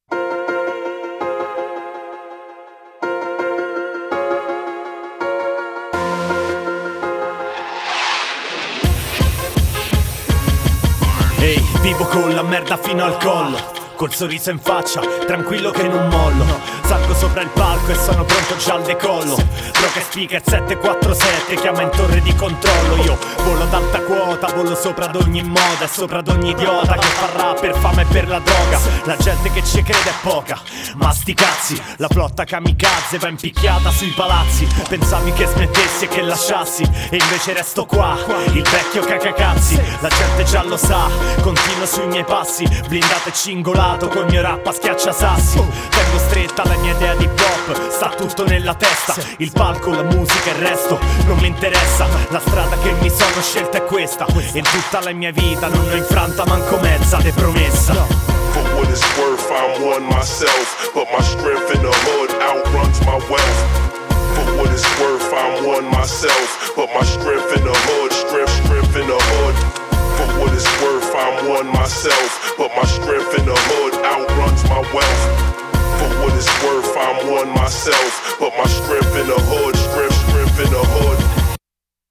brano rap inedito
Passa la vigilia di Natale con del buon e sano rap italiano